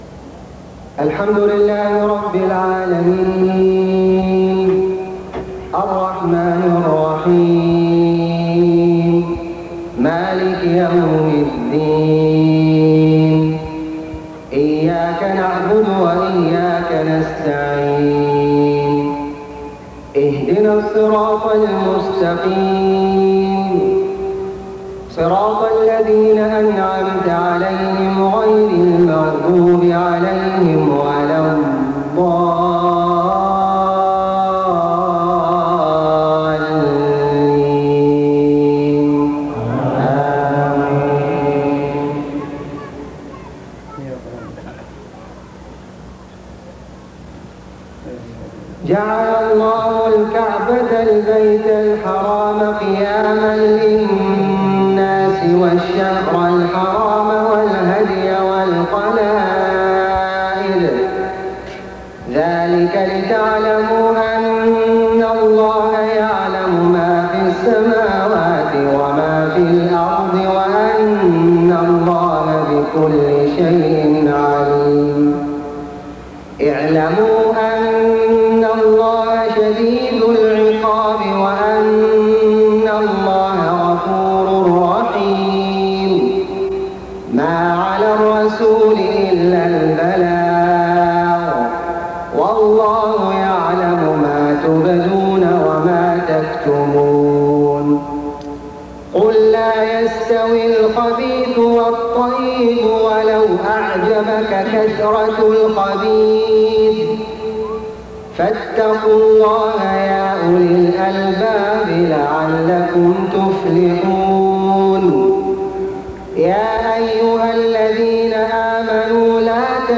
صلاة العشاء 15 محرم 1429هـ من سورة المائدة 97-105 > 1429 🕋 > الفروض - تلاوات الحرمين